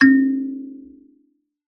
kalimba2_wood-C3-pp.wav